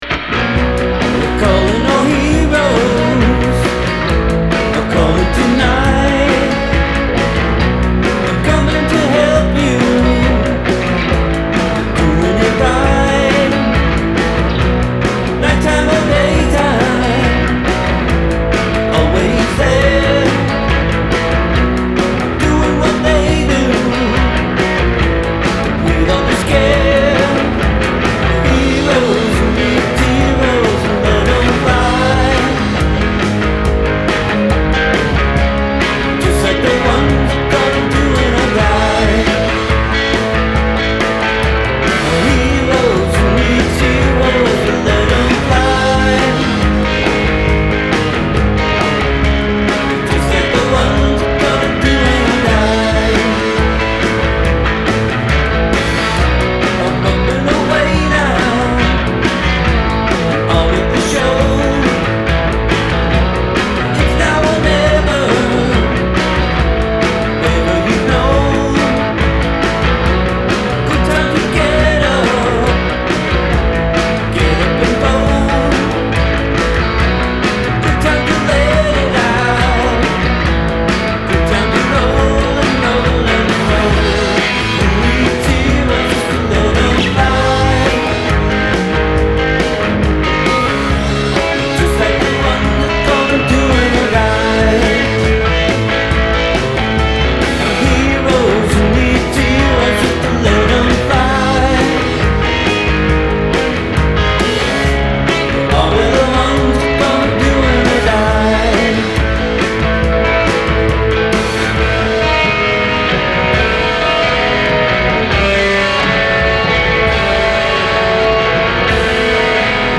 Guitar and Vocals
Drums